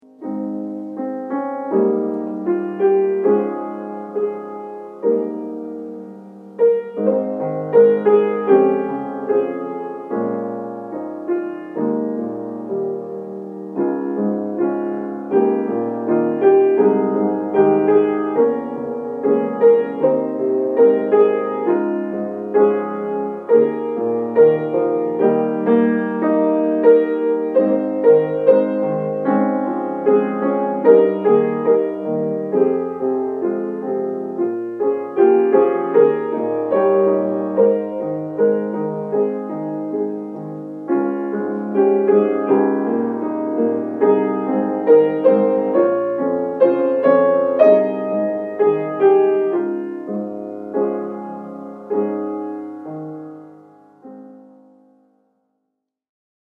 (SATB)